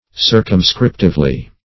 Circumscriptively \Cir`cum*scrip"tive*ly\, adv. In a limited manner.